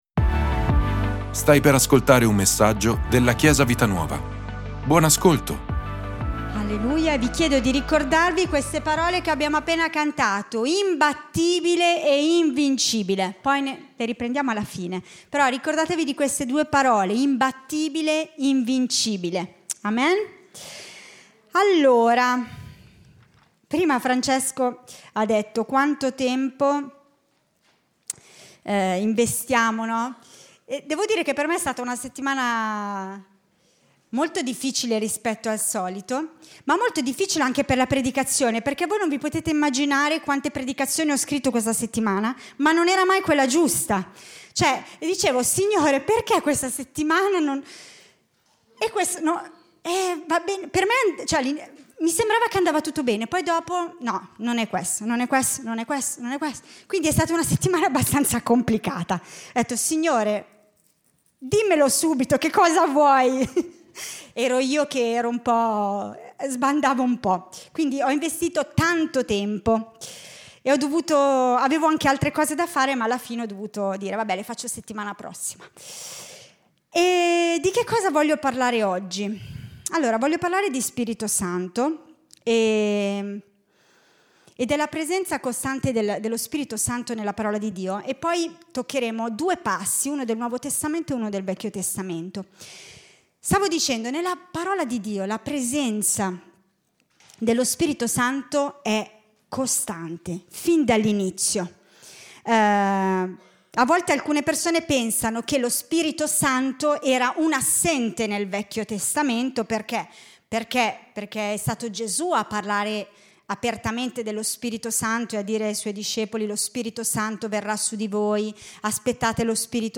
Ascolta la predicazione: 09/26_La potenza in me - Chiesa Vita Nuova